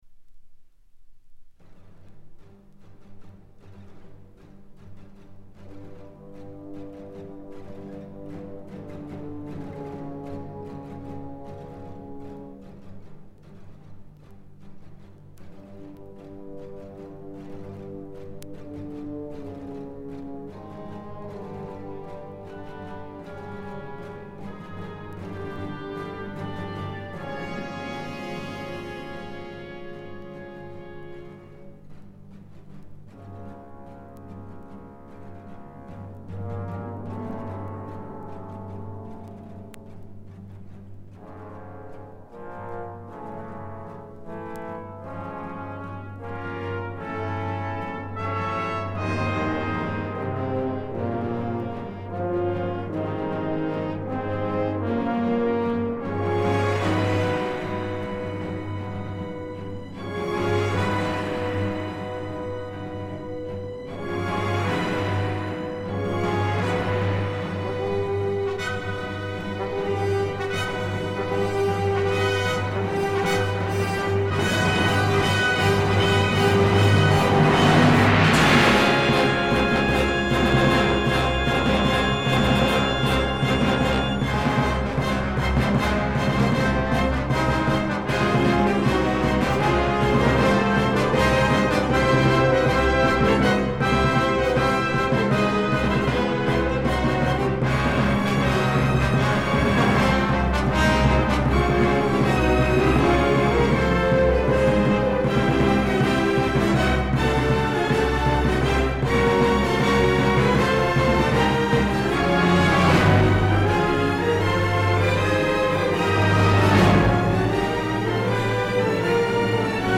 Classical music, orchestra